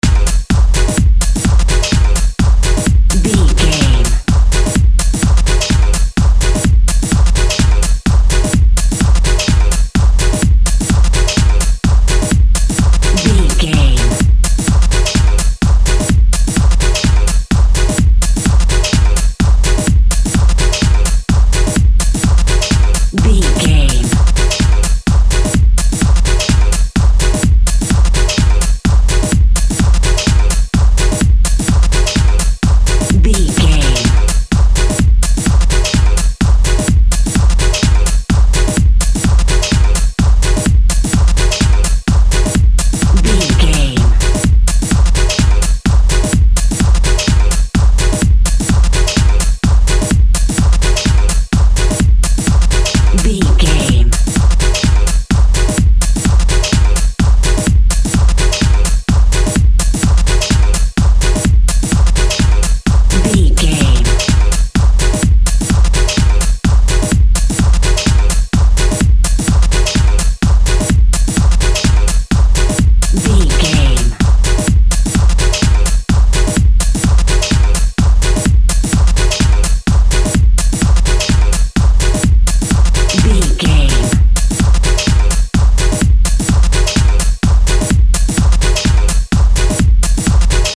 Epic / Action
Fast paced
Aeolian/Minor
G#
intense
futuristic
energetic
driving
repetitive
dark
synthesiser
drums
drum machine
techno
electro house
progressive house
synth lead
synth bass